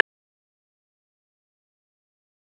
Tm8_HatxPerc4.wav